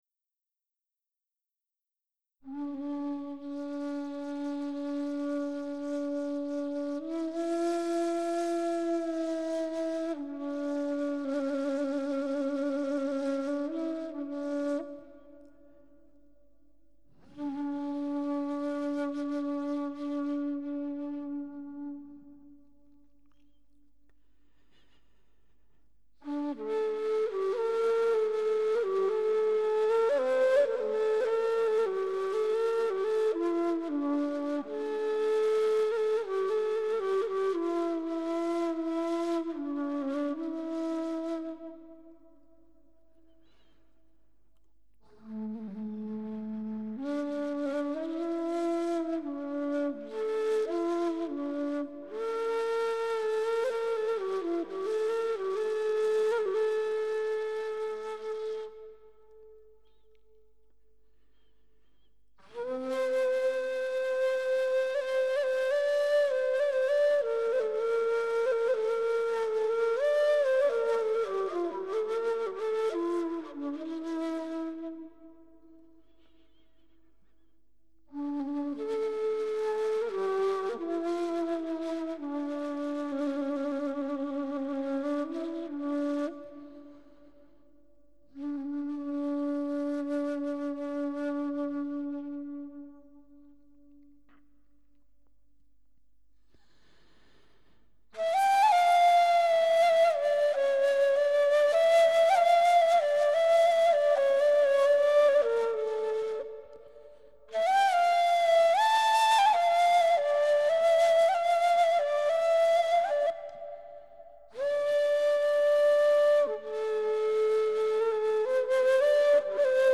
More Sufi music